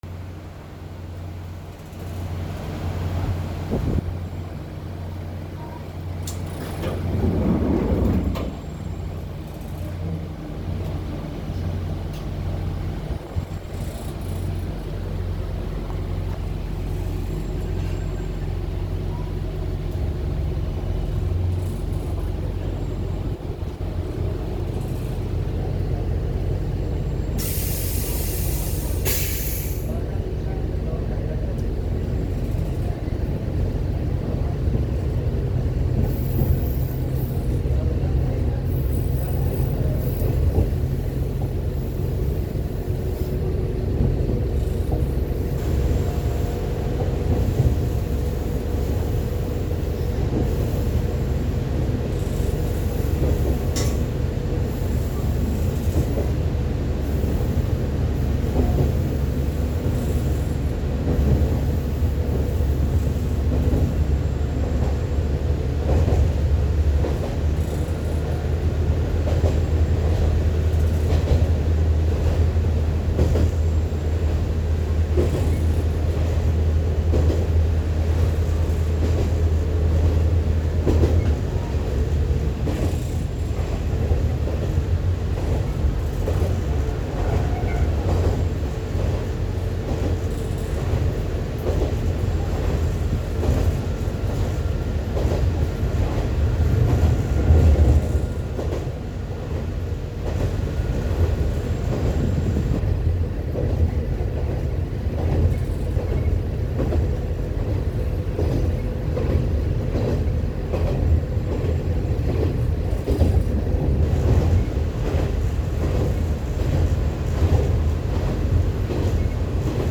・キハ532形走行音
動き出してしまえばごく一般的なディーゼルカーの音で、他の車両と比べて騒がしいという事も特にありません。
sanuki-ireji.mp3